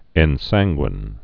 (ĕn-sănggwĭn)